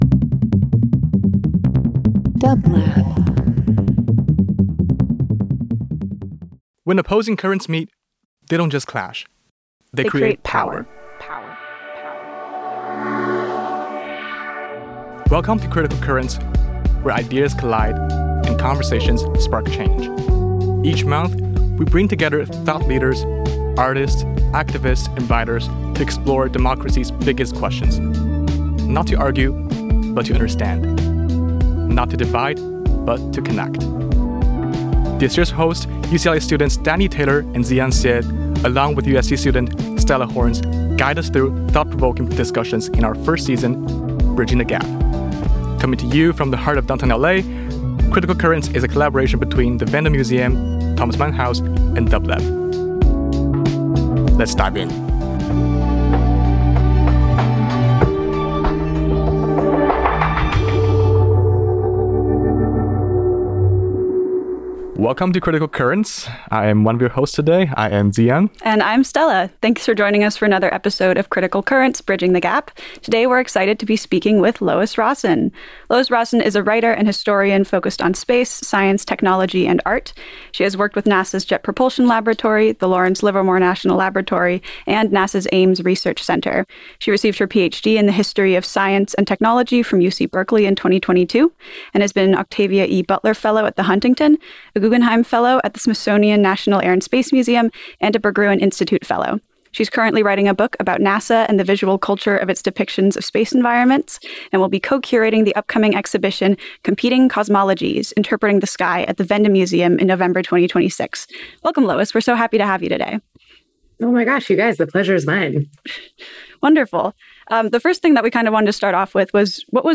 Thomas Mann House Wende Museum Critical Currents: Bridging the Gap 08.28.25 Interview Democracy thrives on open dialogue, diverse perspectives, and the courage to engage across differences.